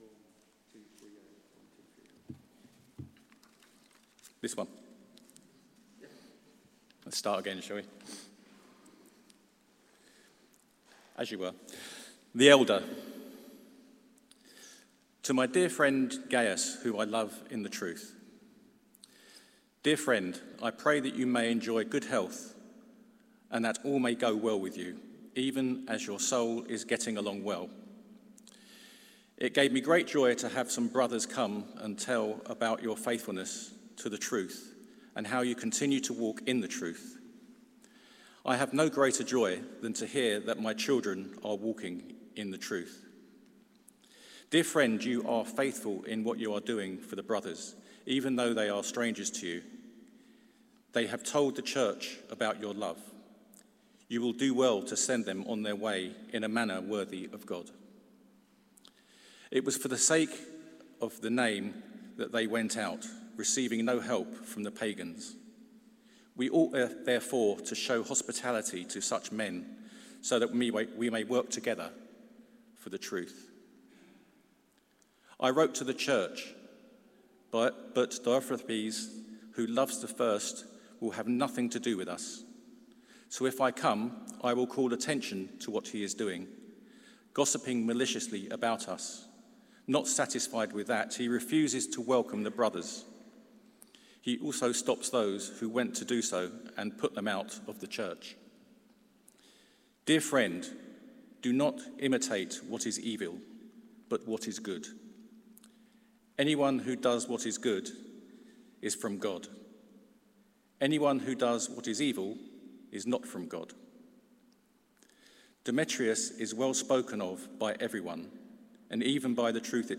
Theme: World Mission Sunday Sermon